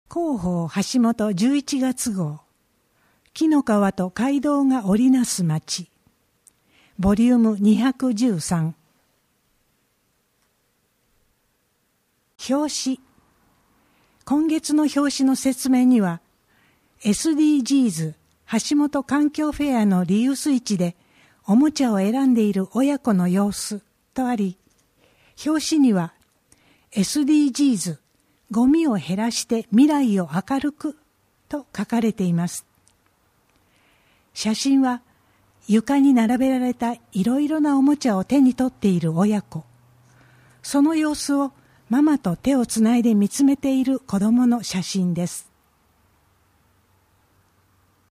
WEB版　声の広報 2023年11月号